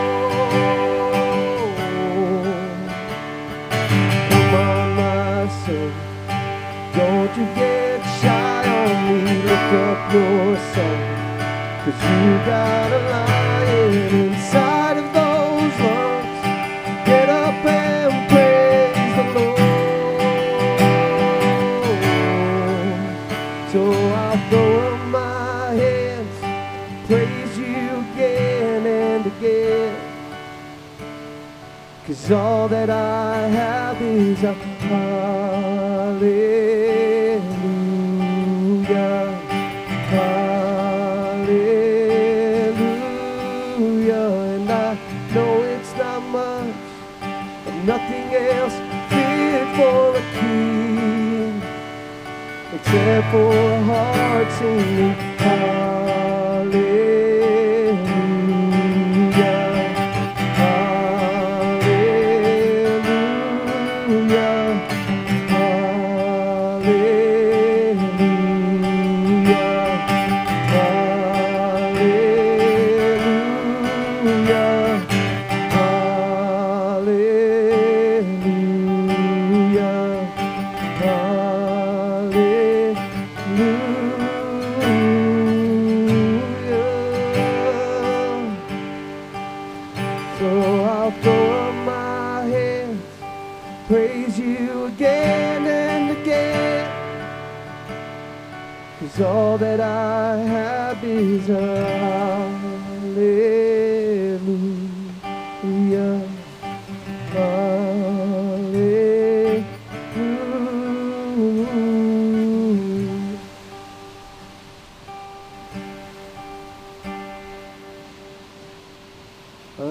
SERMON DESCRIPTION Joseph revealed himself to his brothers with tears, showing how guilt can fill us with fear.